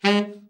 TENOR SN  13.wav